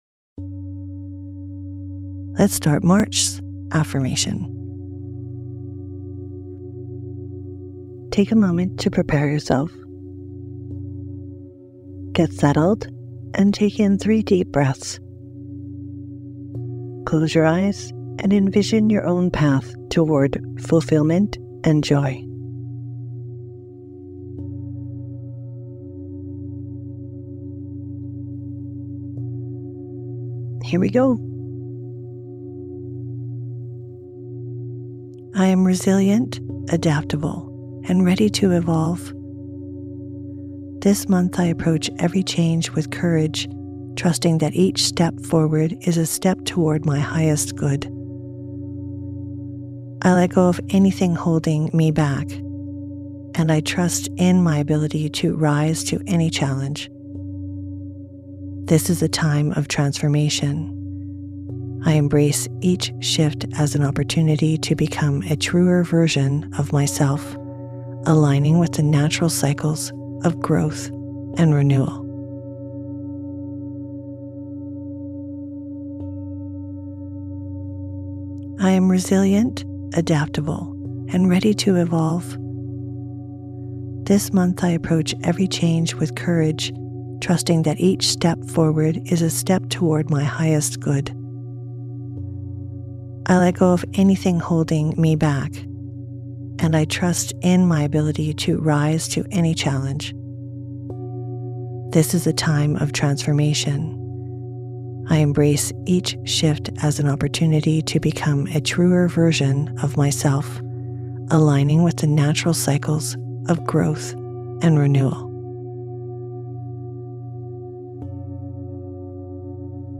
Sleep versions feature the affirmation repeated three times, fostering repetition for deeper impact and greater benefits.